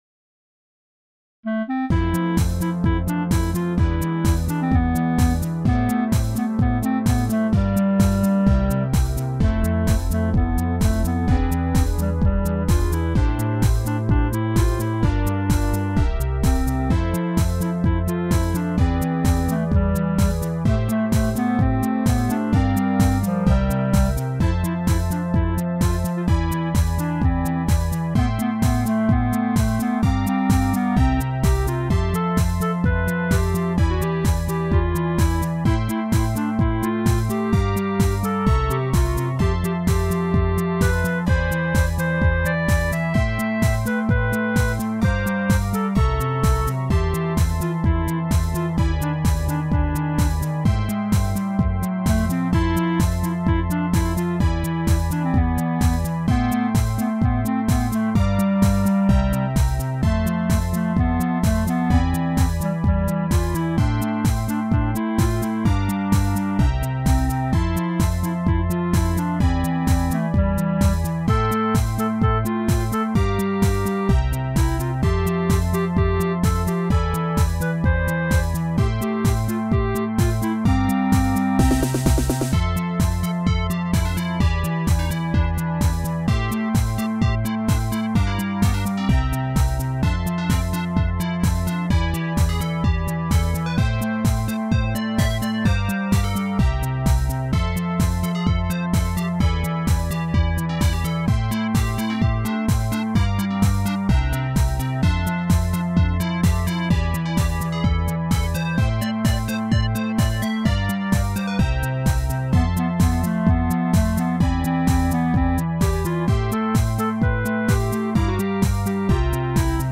BGM
インストゥルメンタルポップロング